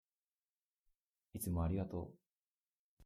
癒し声vol.1
「ああ、こんなふうに言われてみたかった」 ナースのための「癒し声」コーナーがはじまりましたよ。